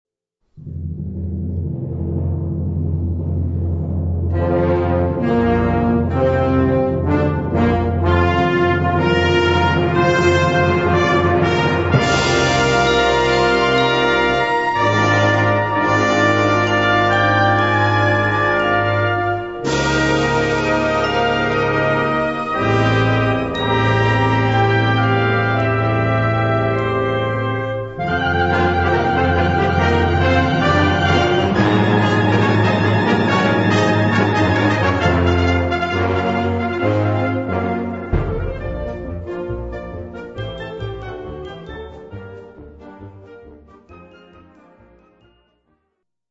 Unterkategorie Konzertmusik
Besetzung Ha (Blasorchester)